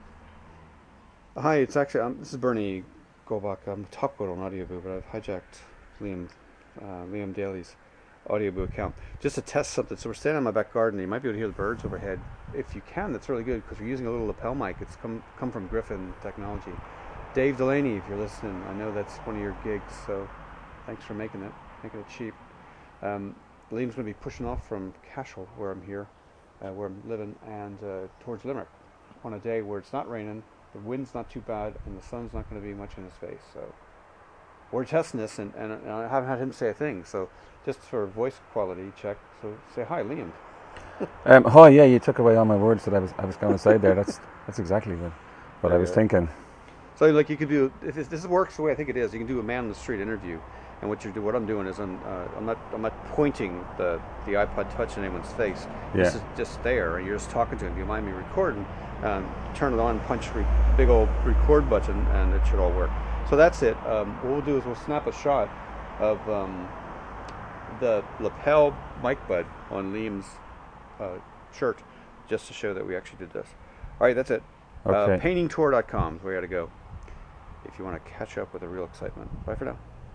Lapel Mic Check